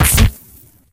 ~tele_damage_1.ogg